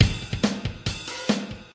Lil Country Trap Aucostic Guitar2 140
描述：这些东西只适合于乡村或乡村陷阱。 你决定吧 享受140Bpm
标签： 140 bpm Country Loops Guitar Acoustic Loops 2.31 MB wav Key : E Reason
声道立体声